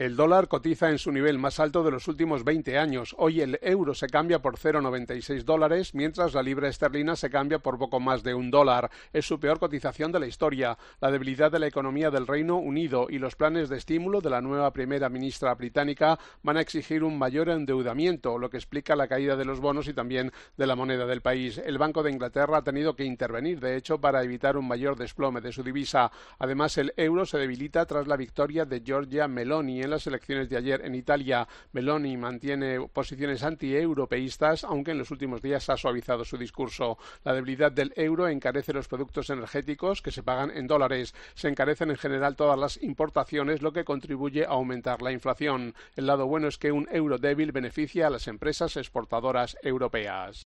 Te da más detalles de la caída de la libra esterlina el experto en bolsa